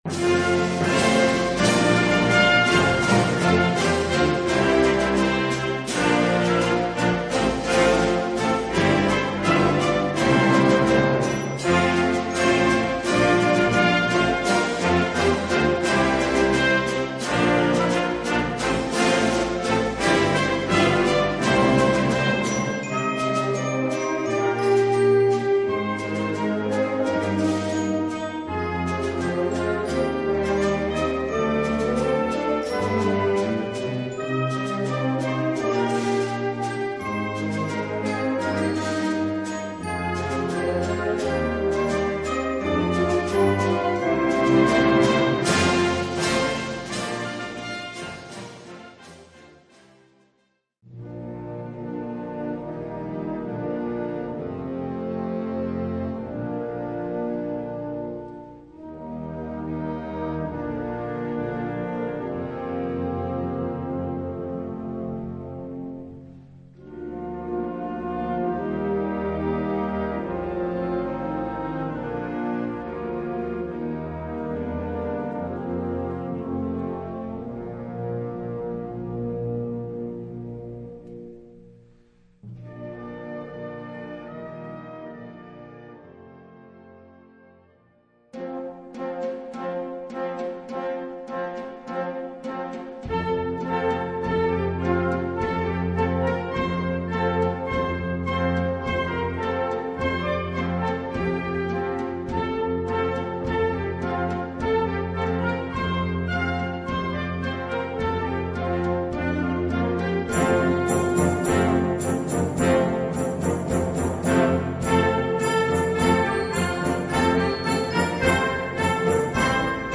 Gattung: Konzertstück
Besetzung: Blasorchester
ist sehr ruhig und lädt zur Meditation ein.